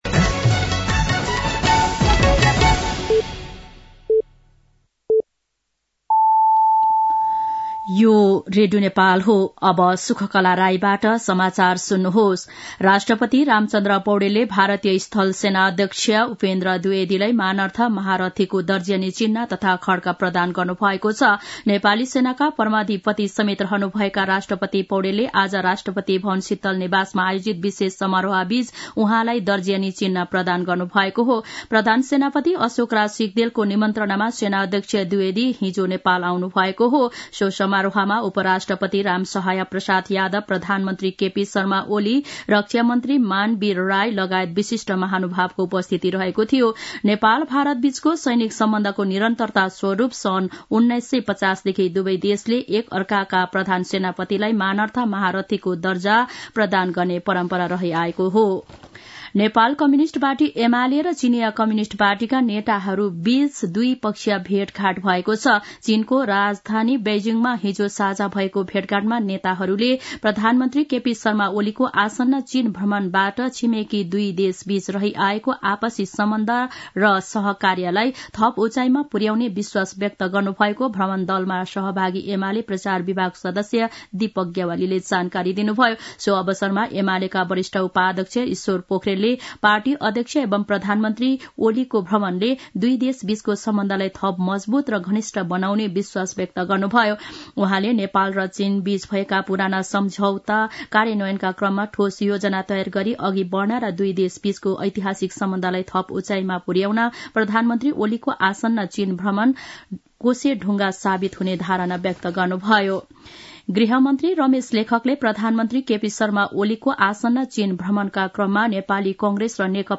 दिउँसो ४ बजेको नेपाली समाचार : ७ मंसिर , २०८१
4-pm-nepali-news-1-3.mp3